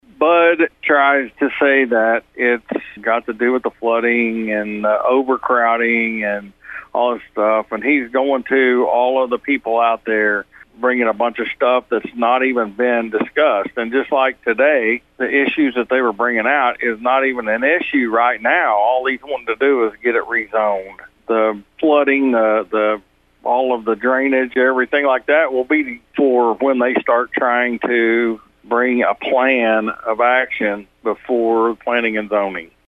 At Monday's Osage County Commissioners meeting, a group of citizens were there to voice their displeasure about the Board considering taking a first step that would allow a housing development to go up on a plat of land near Skiatook.